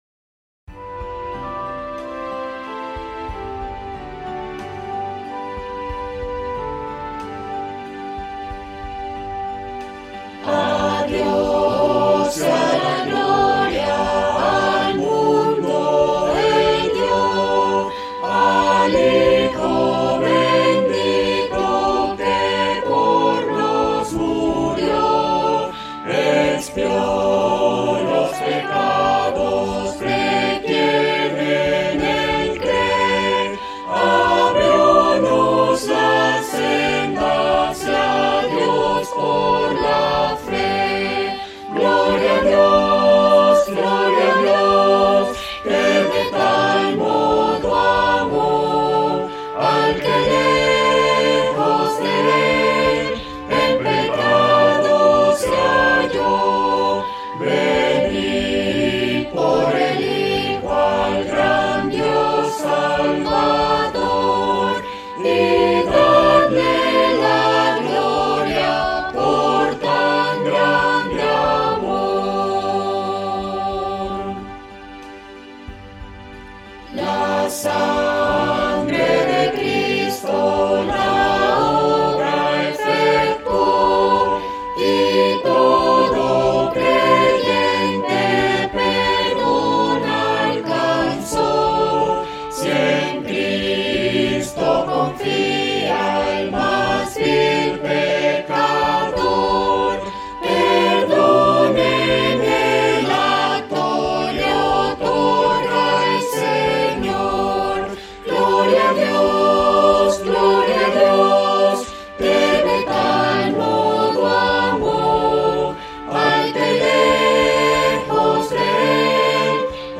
Cantado (Descargar audio)